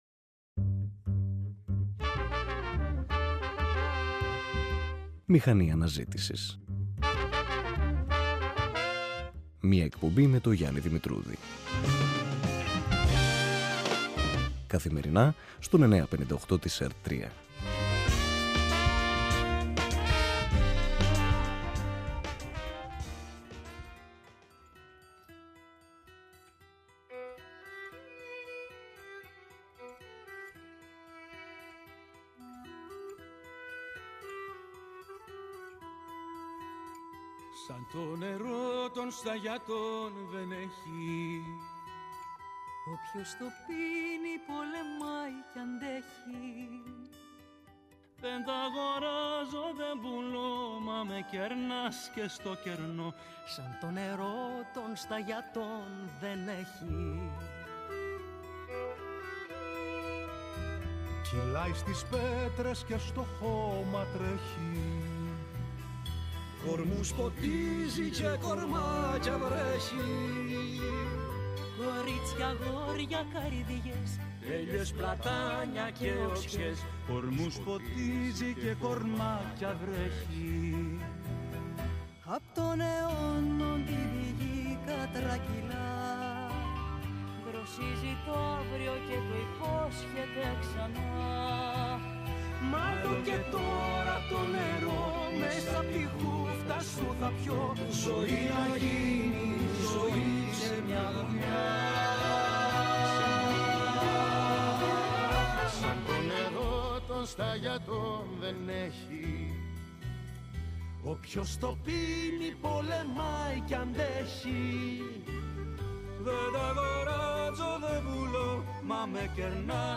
ΜΗΧΑΝΗ ΑΝΑΖΗΤΗΣΗΣ | ΣΥΝΕΝΤΕΥΞΗ
Μέσω τηλεφωνικής επικοινωνίας